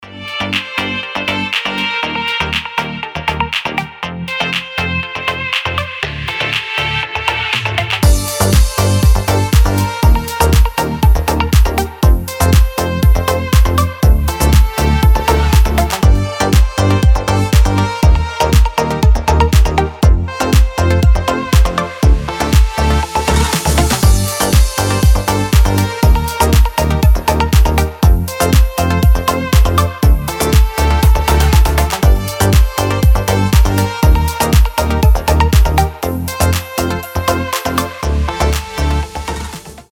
• Качество: 320, Stereo
без слов
скрипка
Стиль: deep house, nu disco